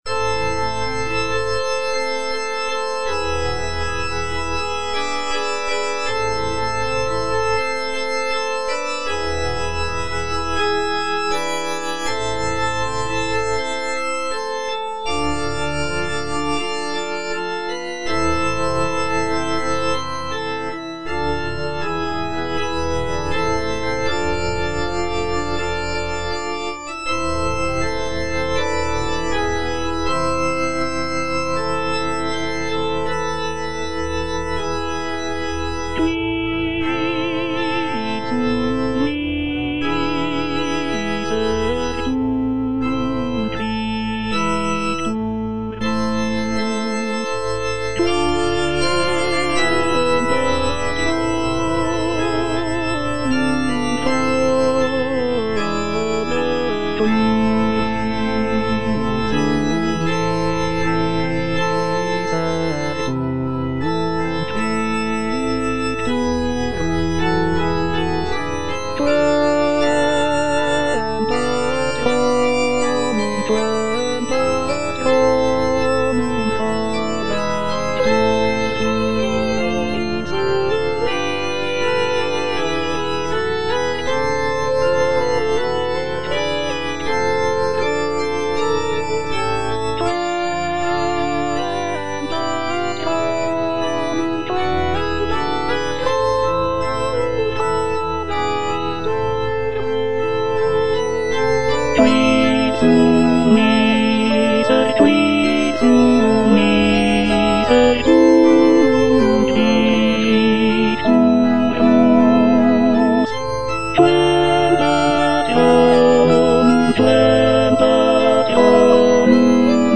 Tenor (Emphasised voice and other voices) Ads stop
is a sacred choral work rooted in his Christian faith.